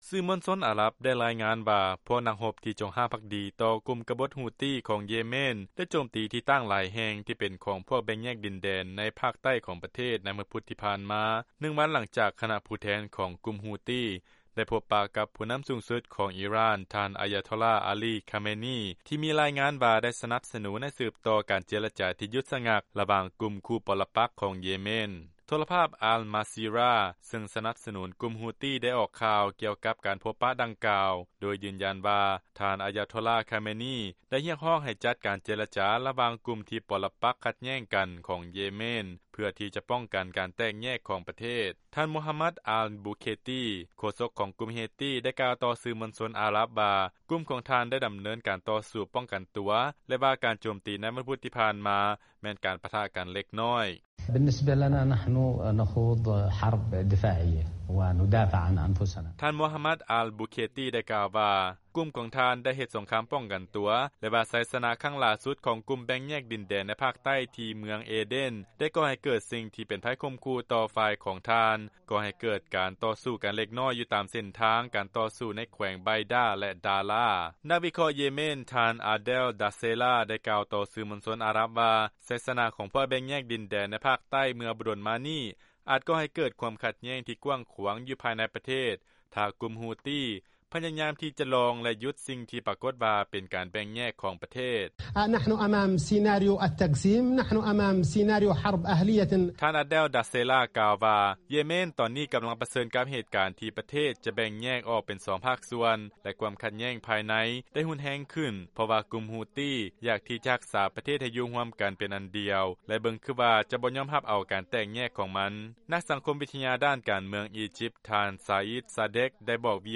ຟັງລາຍງານ ຜູ້ນຳສູງສຸດ ອີຣ່ານ ຮຽກຮ້ອງໃຫ້ ເຢເມນ ຈັດການເຈລະຈາ ເພື່ອປ້ອງກັນ ການແຕກແຍກຂອງປະເທດ